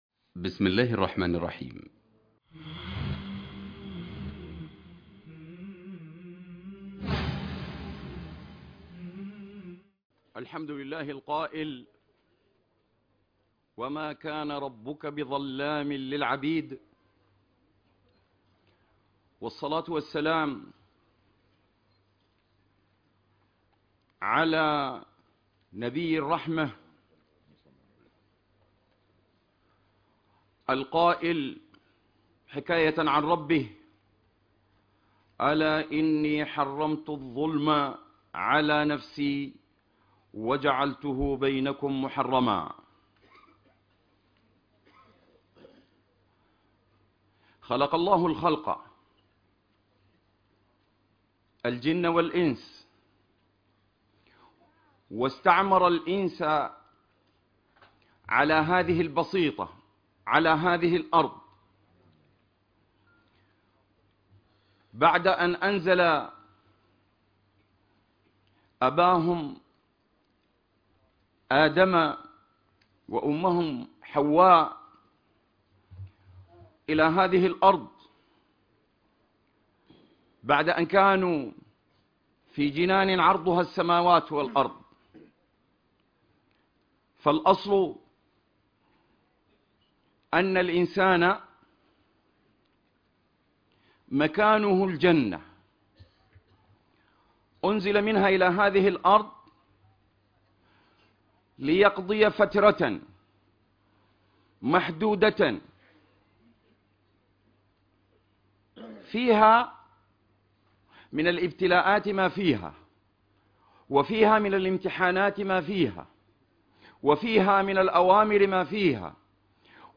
الإرهاب مظاهره - أسبابه - الوقاية منه ندوة بهولندا